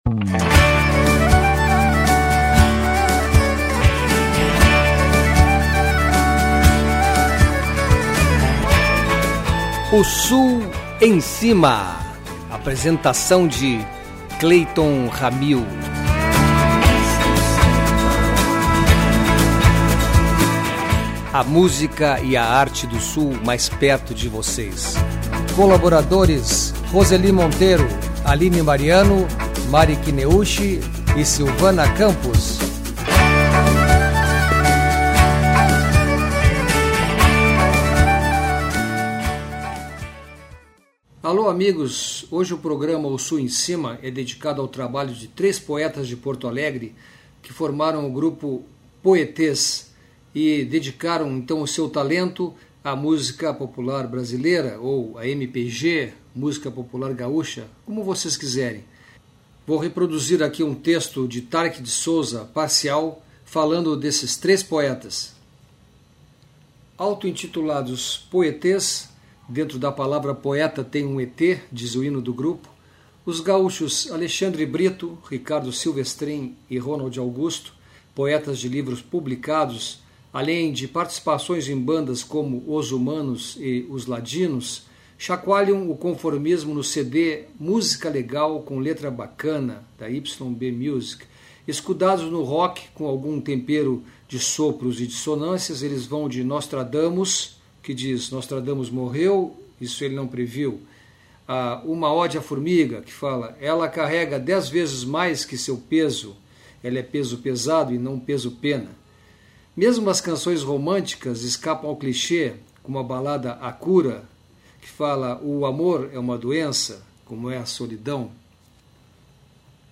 Eles se arriscam como ETs na exploração de novos mundos musicais e propõem música para todas as ocasiões: para tocar no rádio, na festa, no iPod.